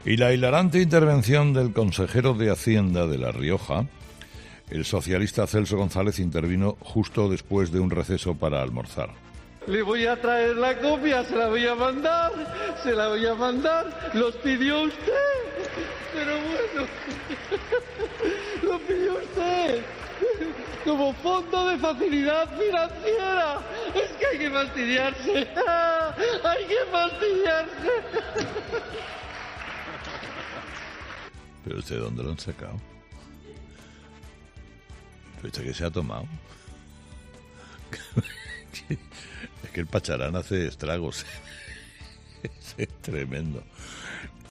Lo raro es que lo hizo con risas descontroladas, gestos exagerados y totalmente descontrolado "Le voy a traer la copia, se la voy a mandar, se la voy a mandar... Lo pidió usted, como fondo de facilidad financiera. ¡Es que hay que fastidarse! ¡Hay que fastidiarse! Porque se había cumplido el objetivo de estabilidad presupuestuaria...", dice ante una Cámara perpleja por la actitud del consejero.